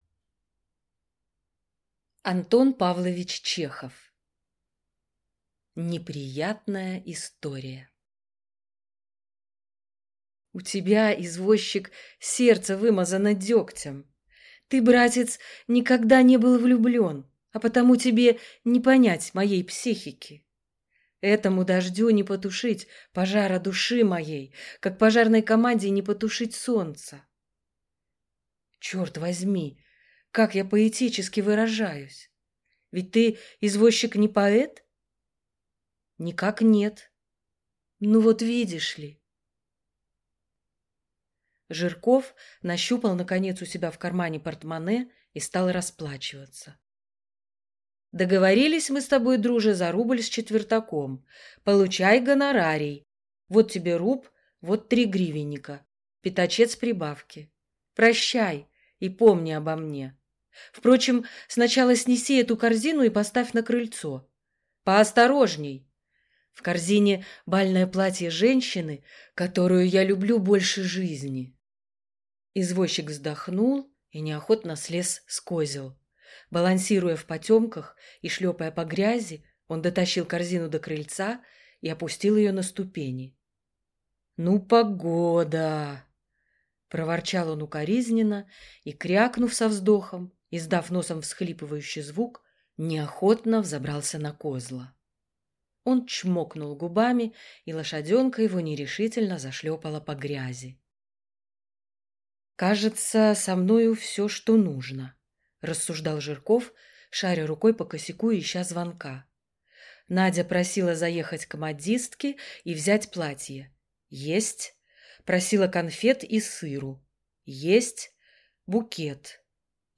Aудиокнига Неприятная история